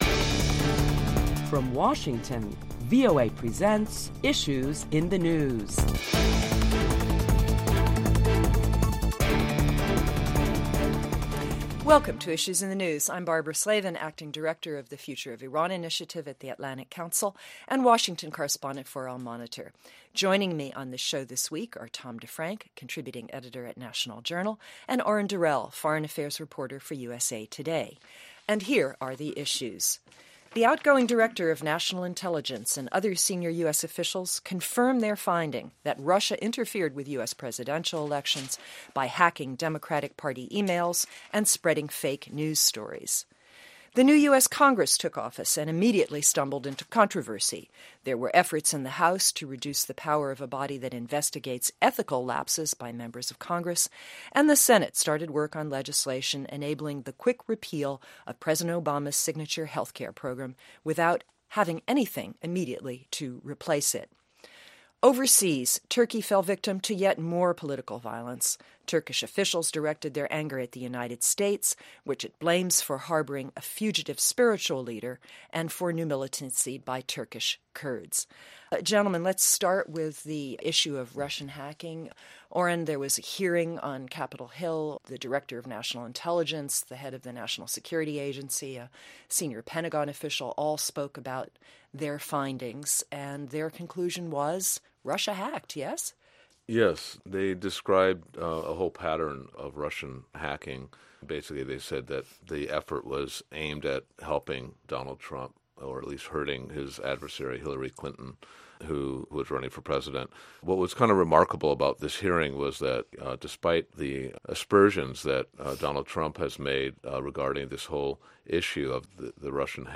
Prominent Washington correspondents discuss topics making headlines around the world.